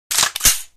ar_unjam.ogg